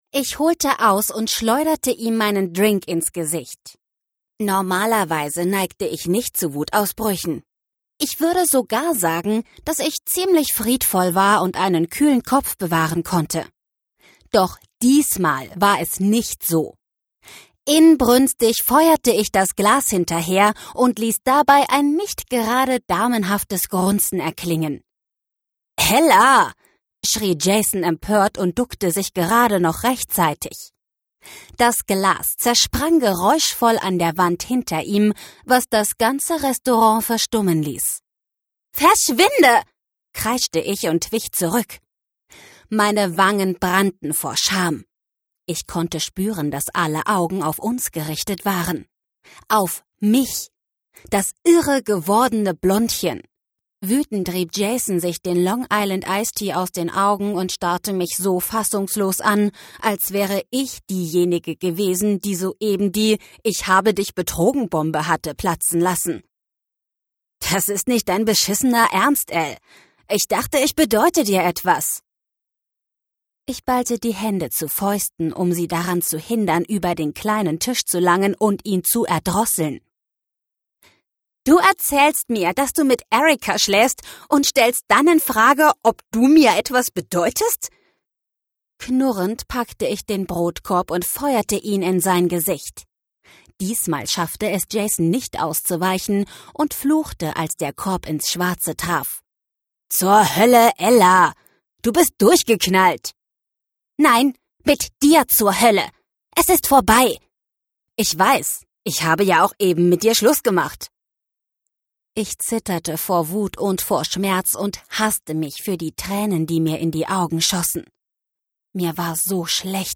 2019 | Leicht gekürzte Lesung
Seine Stimme klingt jung und frisch.